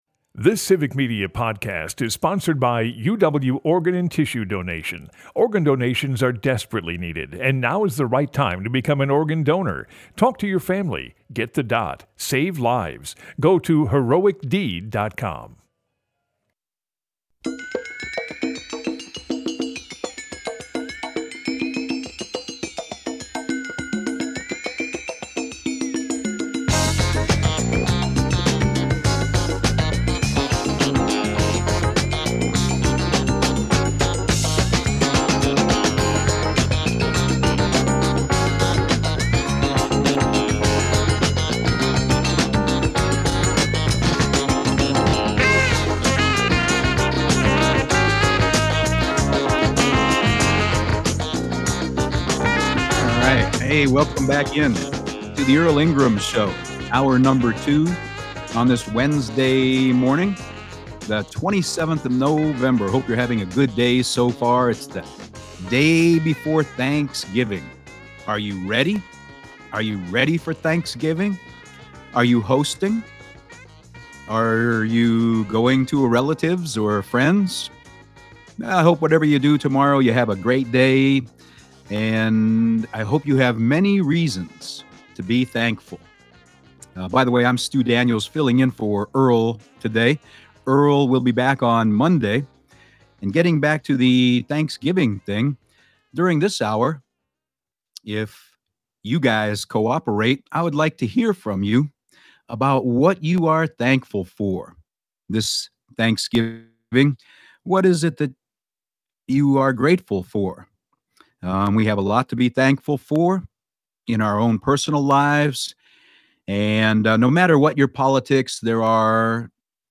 Listeners to the show tell us all just how much there is to be thankful for - a roof over our head, family and friends, and even those who disagree with us.